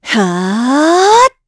Isolet-Vox_Casting1_kr.wav